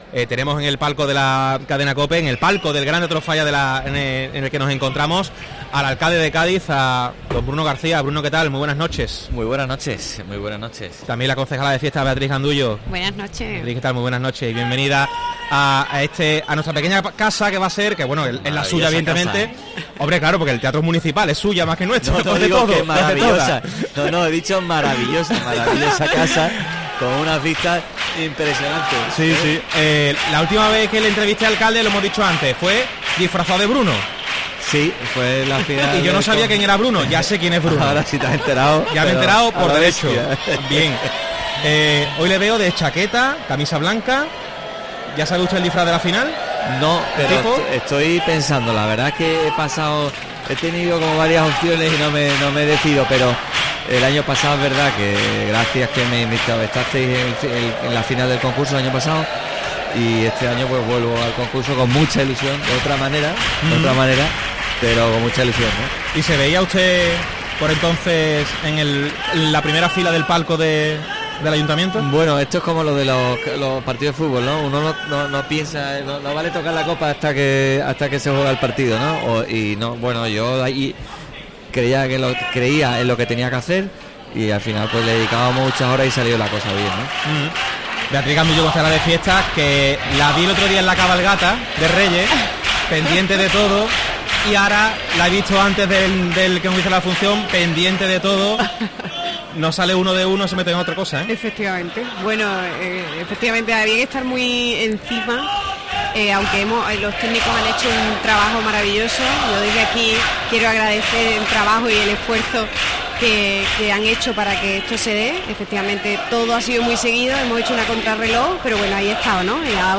Bruno García y Beatriz Gandullo han estado en los micrófonos de COPE en el palco del Gran Teatro Falla. El alcalde y la edil de fiestas han analizado un Concurso de Agrupaciones que ha arrancado con fuerza y que tendrá durante un mes a los aficionados en vilo pendientes del certamen del teatro gaditano.